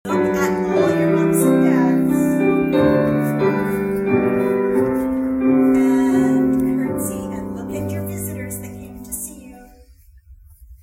The community hall at the myFM Centre was filled with pretty music, smiling family members and the cutest little ballerinas as the Town of Renfrew’s youth ballet program wrapped up for the season Thursday.
may-16-ballet-visitors.mp3